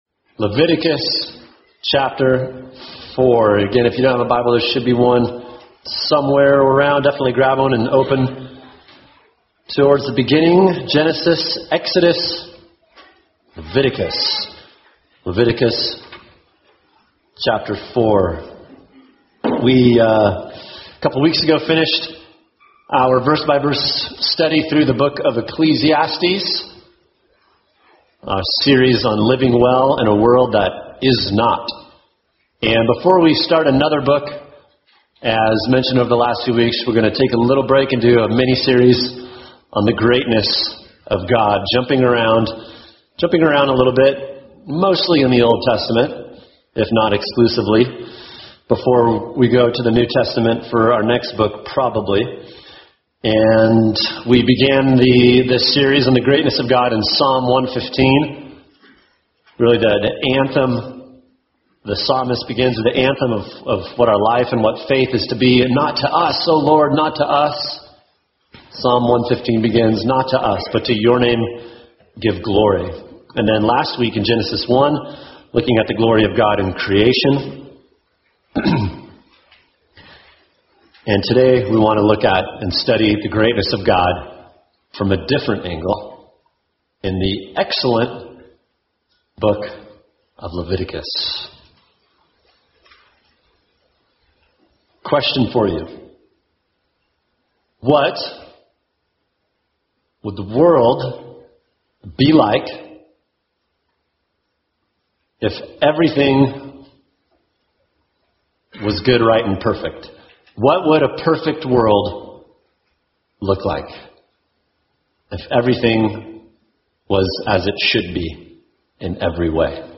[sermon] Leviticus 4 – The Greatness of God: Why So Much Blood?